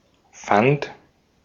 Ääntäminen
Ääntäminen Tuntematon aksentti: IPA: /fant/ Haettu sana löytyi näillä lähdekielillä: saksa Käännöksiä ei löytynyt valitulle kohdekielelle. Fand on sanan finden imperfekti.